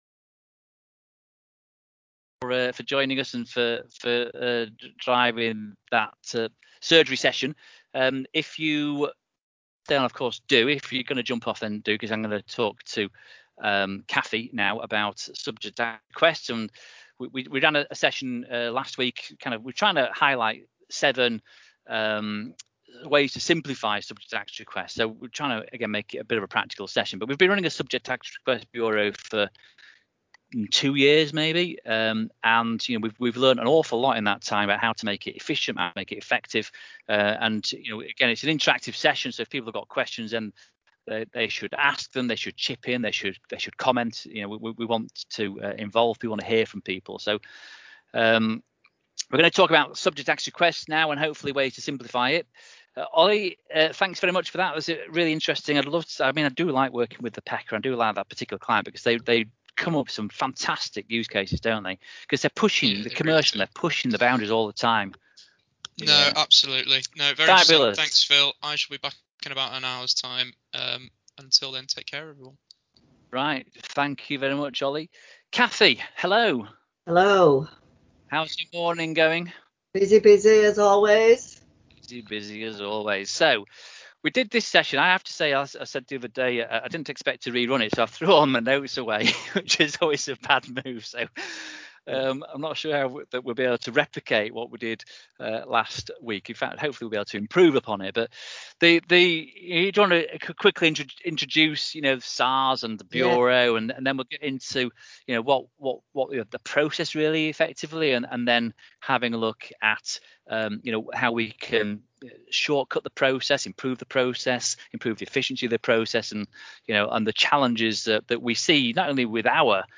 For Data Protection Day we ran a 6-hour long webinar where we spoke about various different topics and attempted to provide free support to small businesses.